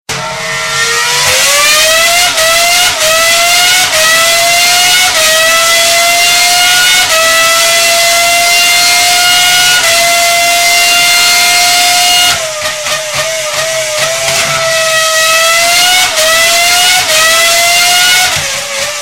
michael schumacher ferrari car sound effects
michael-schumacher-ferrari-car